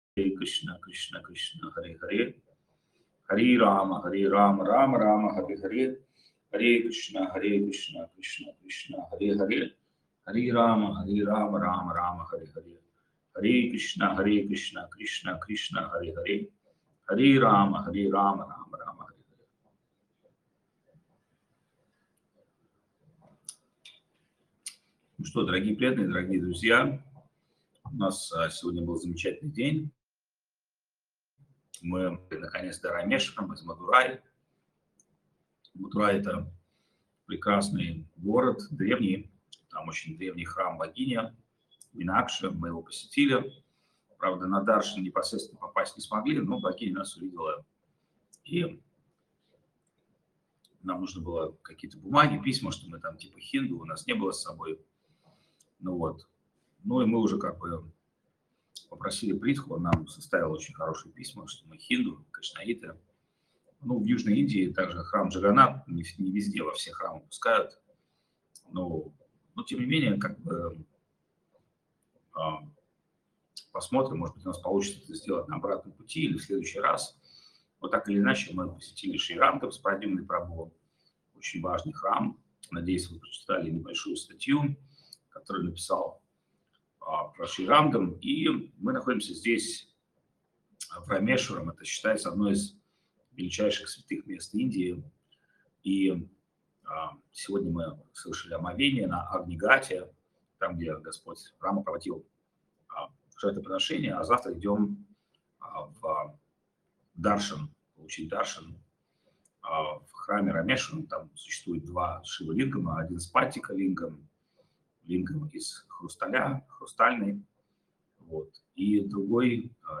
Место: Индия
Лекции полностью